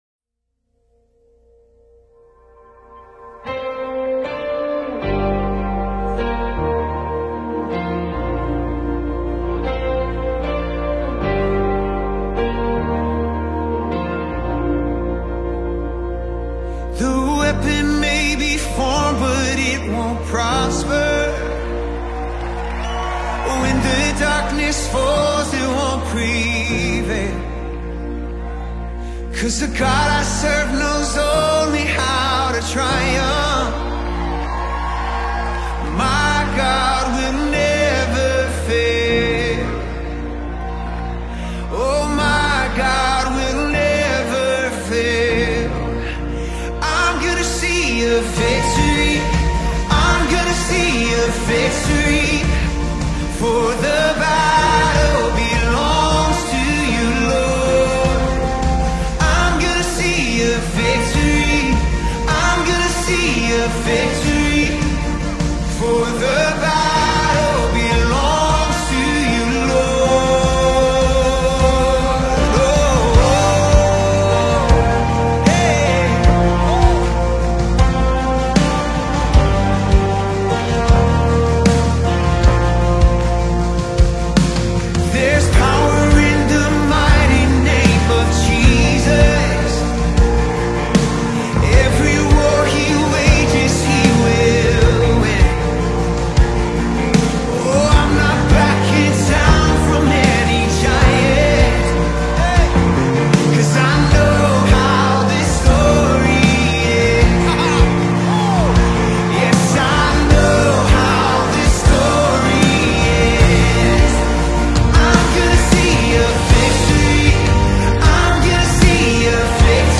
Before we pray, let us take this fiery song of faith.